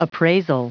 Prononciation du mot appraisal en anglais (fichier audio)
Prononciation du mot : appraisal
appraisal.wav